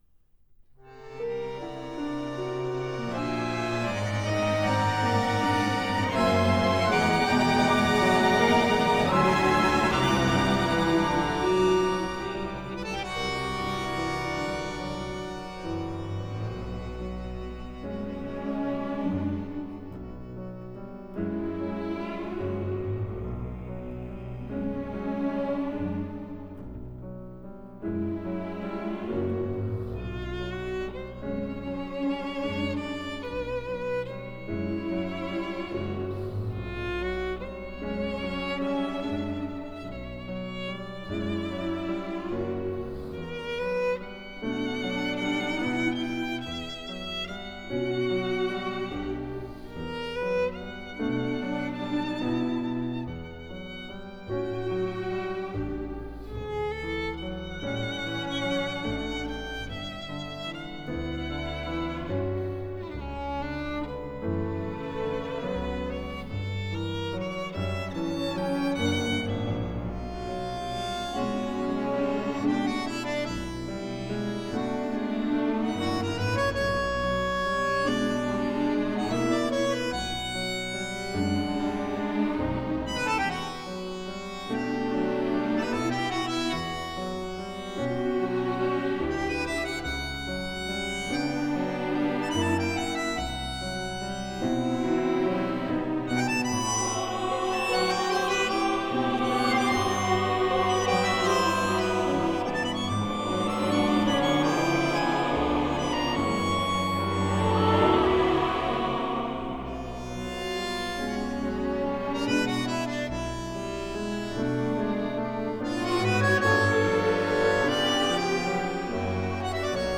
Genre: Tango
Recorded at MCO Hilversum, The Netherlands, April 2002.